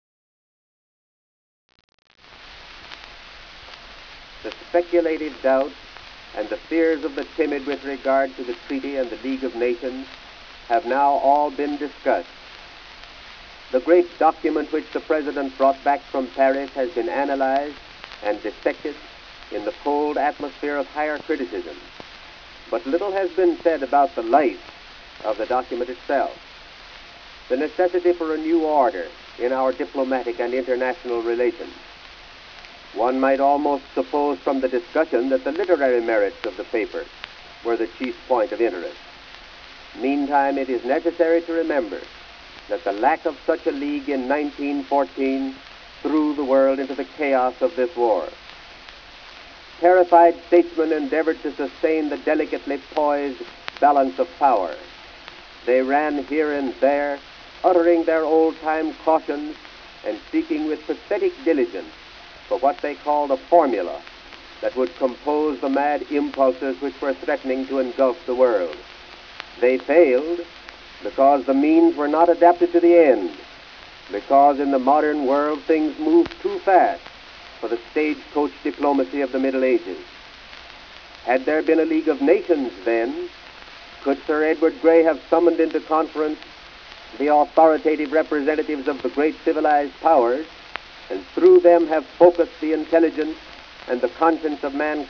AUDIO CREDIT: Baker, Newton Diehl, speaker. "On League of Nations." Circa. 1919-1920. American Leaders Speak: Recordings from World War I and the 1920 Election, American Memory Collections, Library of Congress.